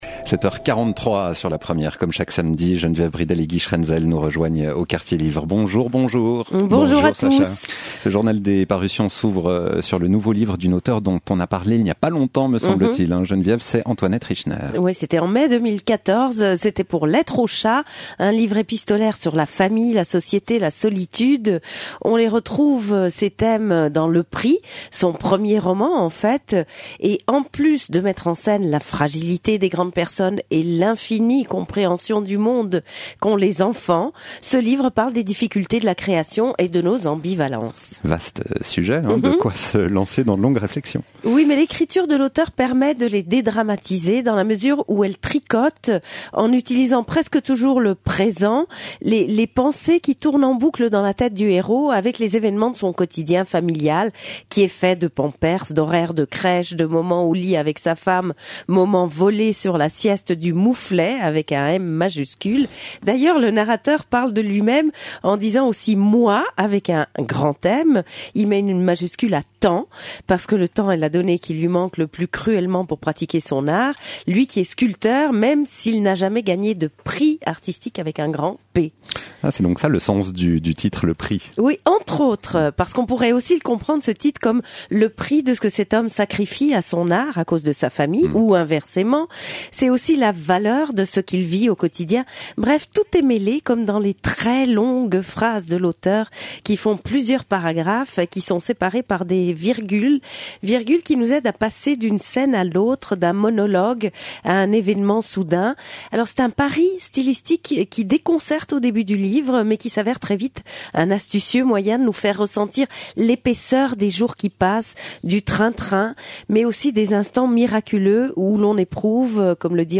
Entretiens et critiques radio :